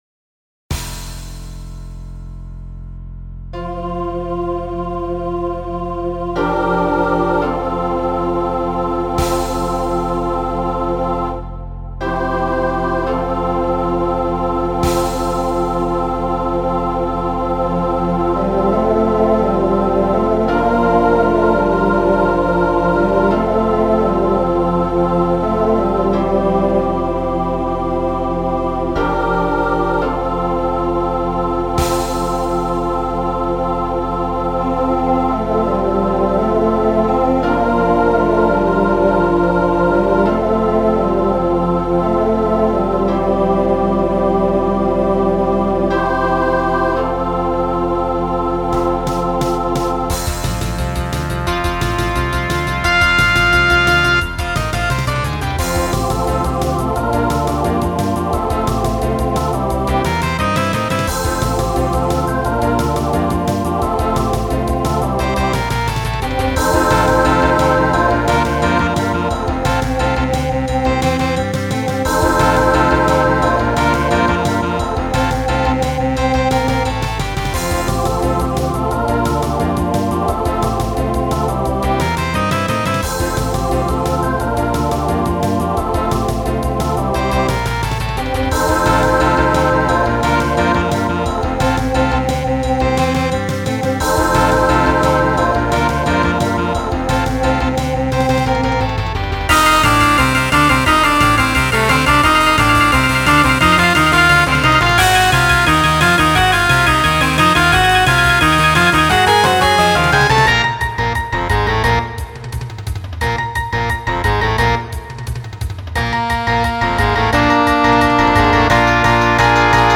Jive arrangement
Voicing SATB
Genre Rock
Mid-tempo